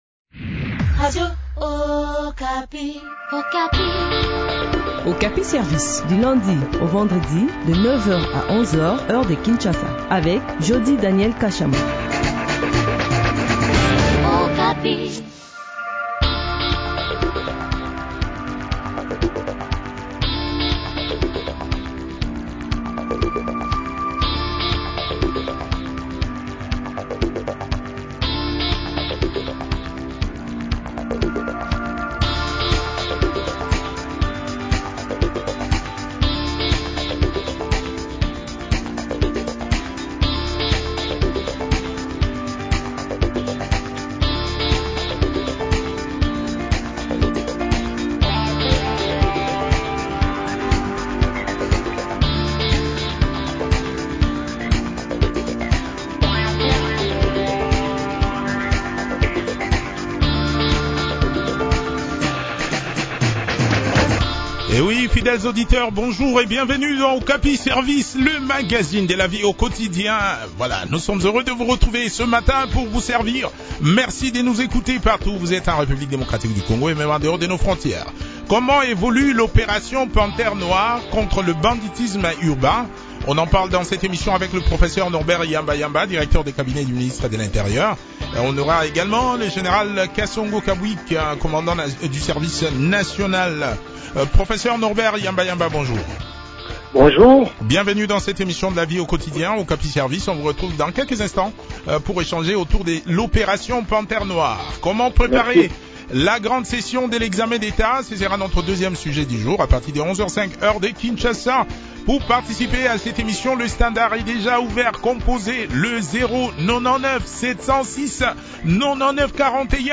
Le général Kasongo Kabwik, commandant du Service National (SN) a également pris part à cette interview.